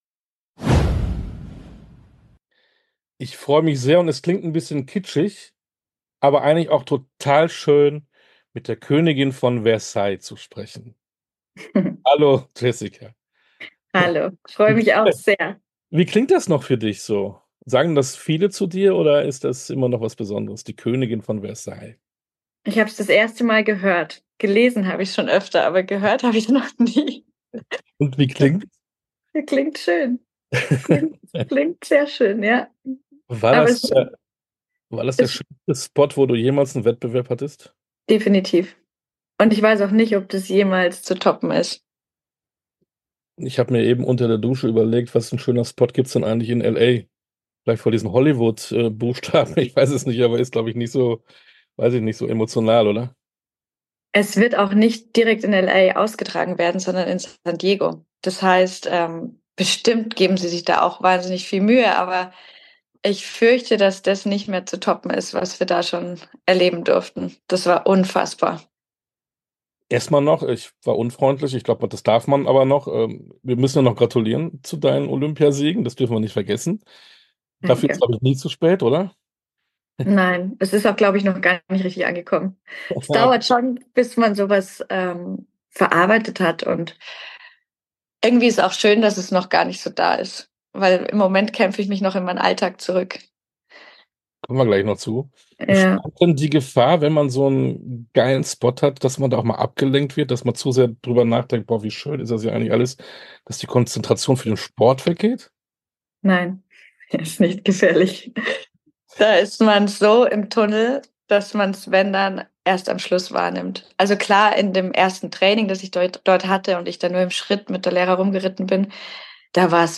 Sportstunde - Interview komplett Jessica von Bredow Werndl, Dressurreiten Olympiasiegerin ~ Sportstunde - Interviews in voller Länge Podcast
Interview_komplett_Jessica_von_Bredow-Werndl-_Dressurreiten_-_Olympiasiegerin.mp3